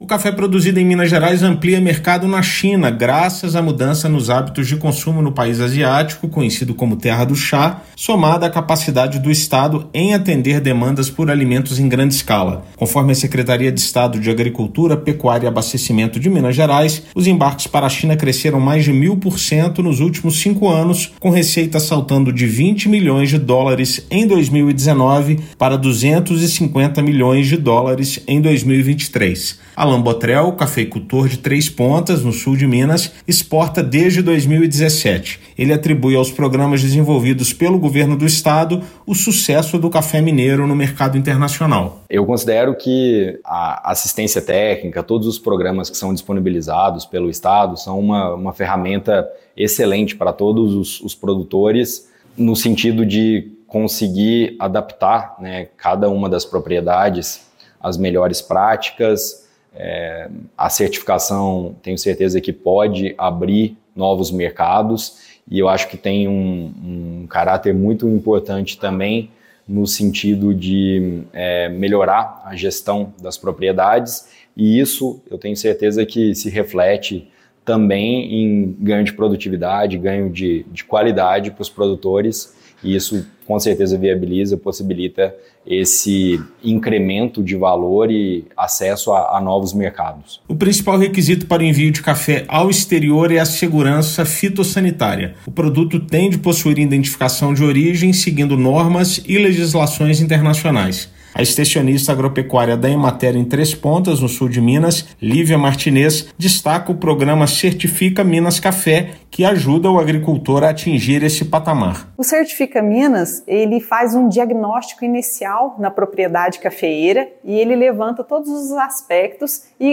De 2019 a 2023, houve crescimento de 1.120% na receita e 780% no volume de exportações do grão; programa Certifica Minas contribui para agregar valor e competitividade ao negócio. Ouça matéria de rádio.